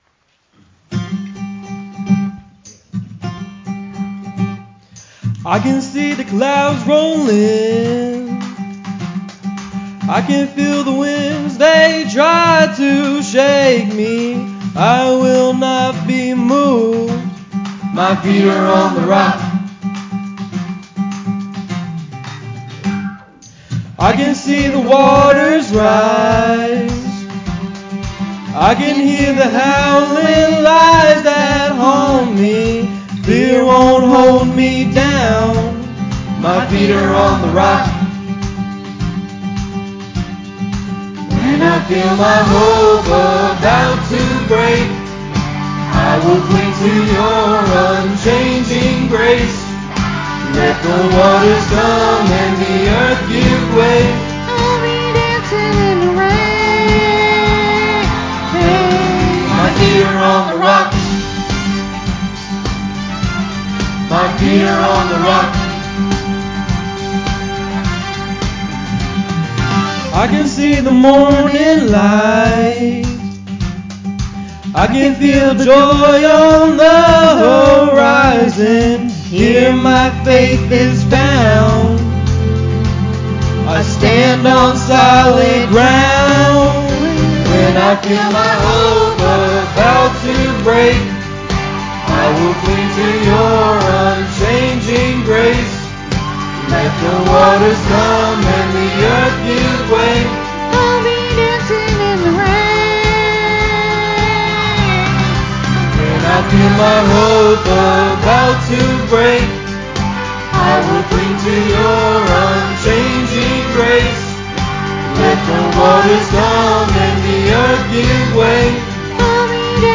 sermonMar09-CD.mp3